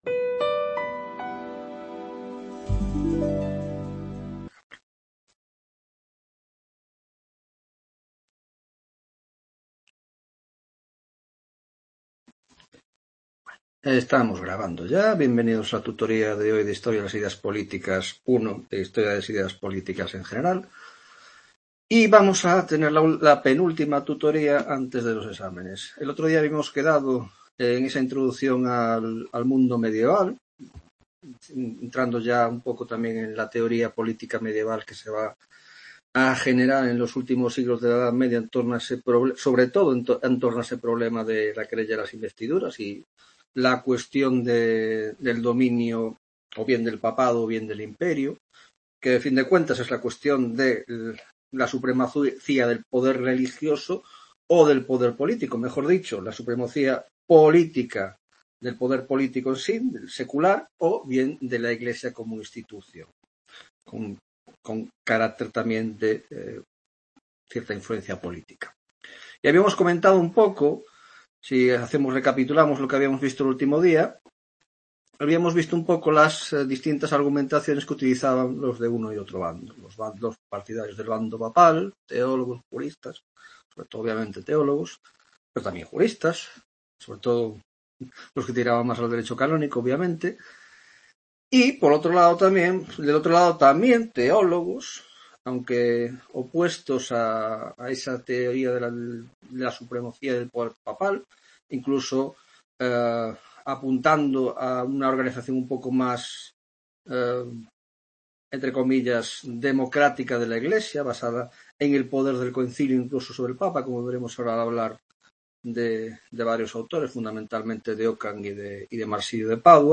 10ª Tutoria de Historia de las Ideas Políticas (Grado de Ciéncias Políticas y Grado de Sociologia)